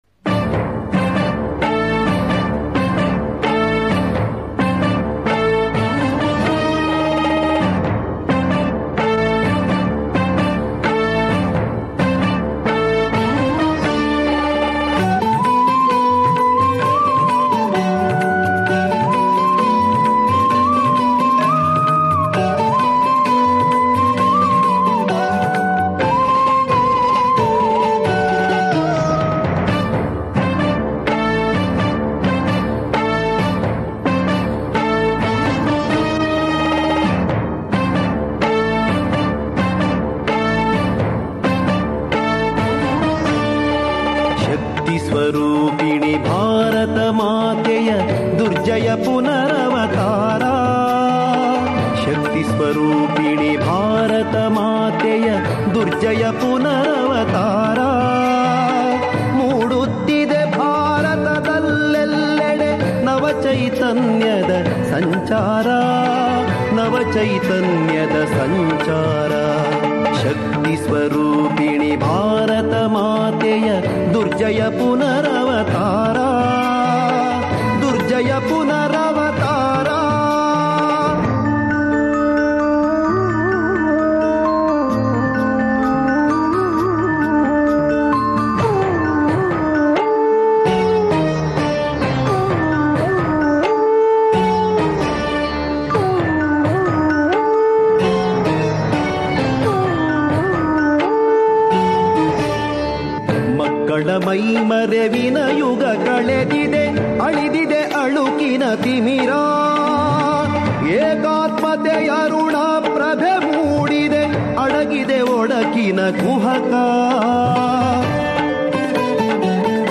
Patriotic Songs Collections